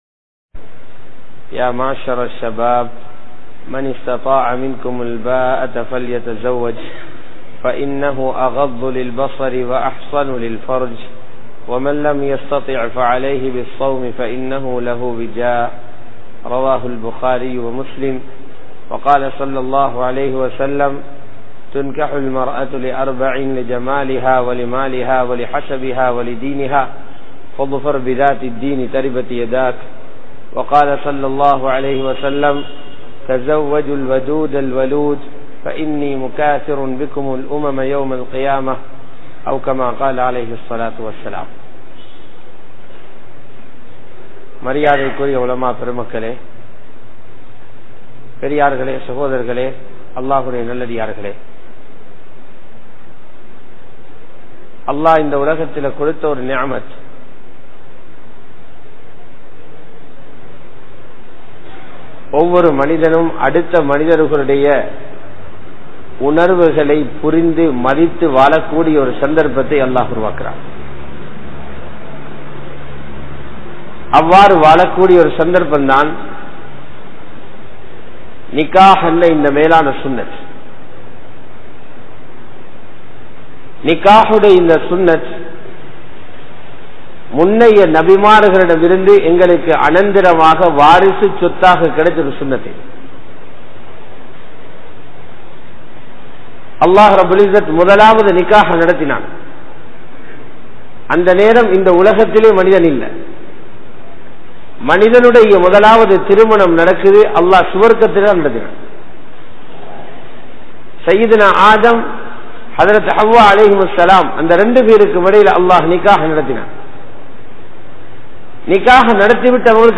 Thirumanathin Noakkam Enna? (திருமணத்தின் நோக்கம் என்ன?) | Audio Bayans | All Ceylon Muslim Youth Community | Addalaichenai
Muhiyadeen Jumua Masjith